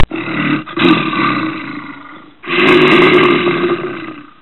Sonneries » Sons - Effets Sonores » L’ours pleure et grogne